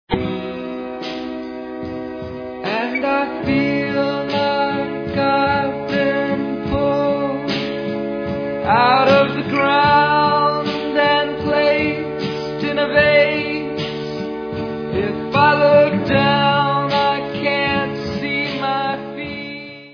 Fragile folk rock a la nick drake